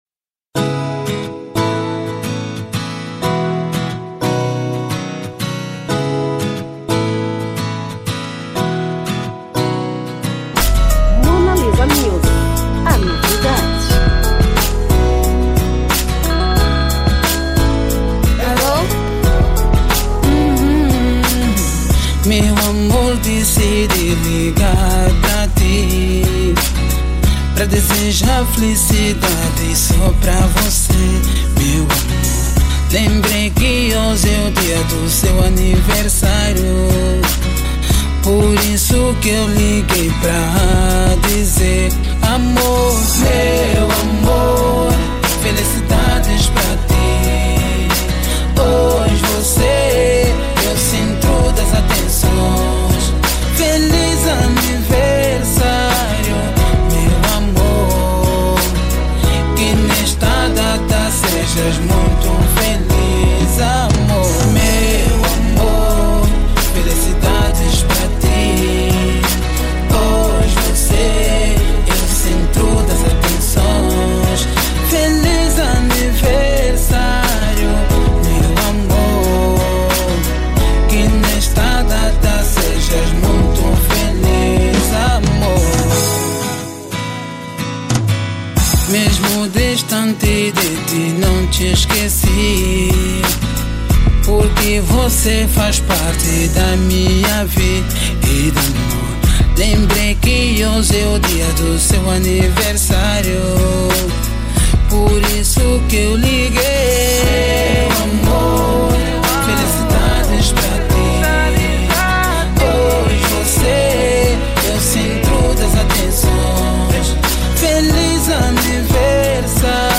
Gênero: Pop/R&B